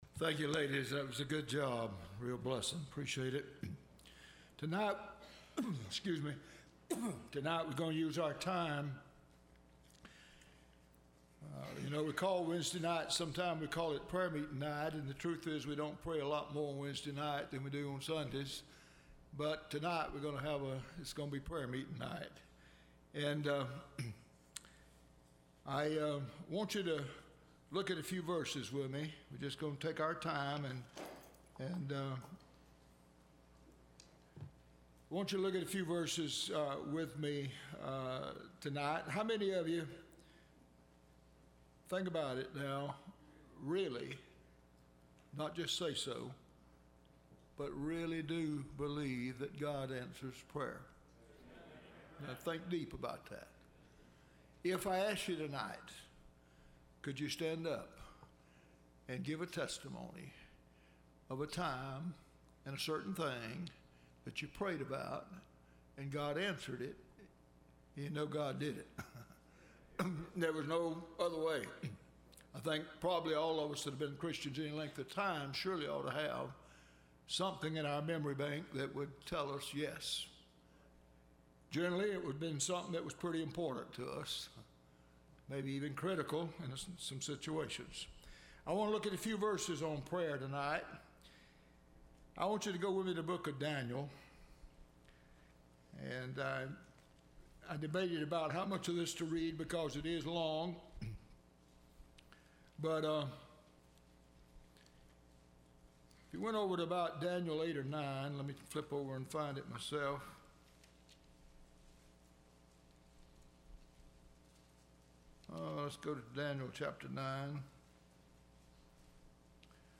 Prayer Meeting for Hurricane Irma – Landmark Baptist Church
Service Type: Wednesday